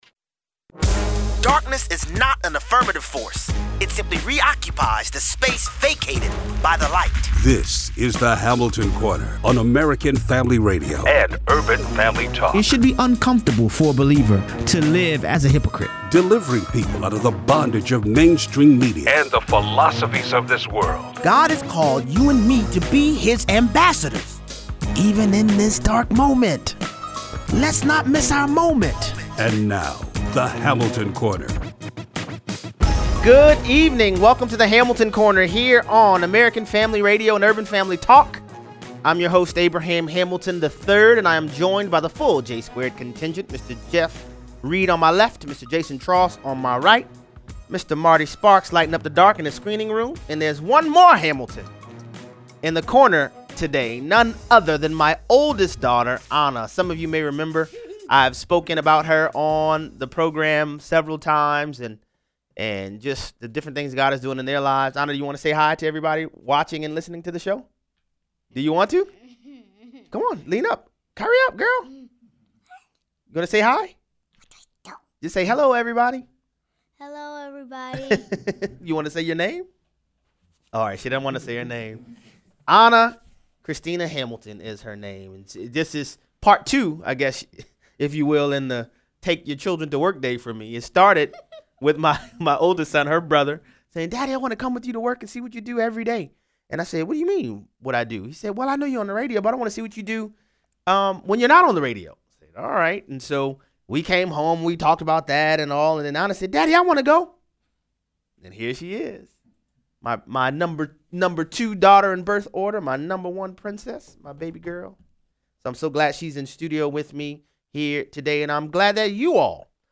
Callers weigh in.